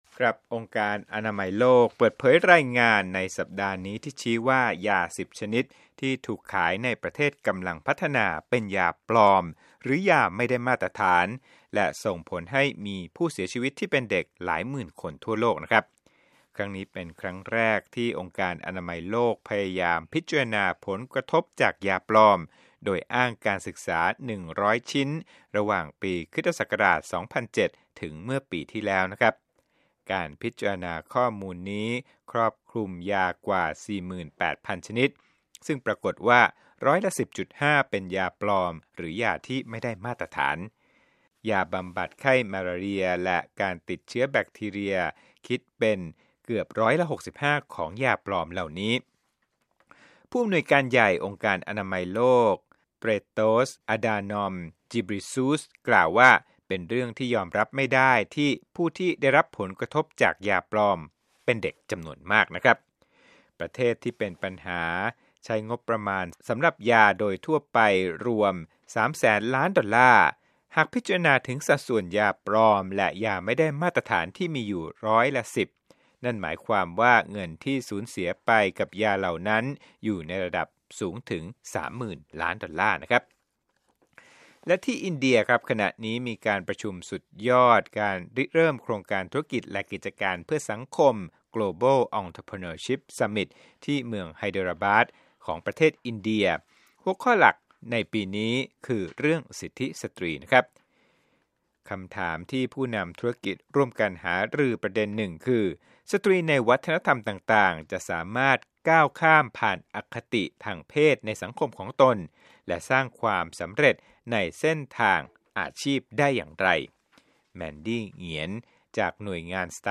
รวมข่าวธุรกิจ 11/29/2017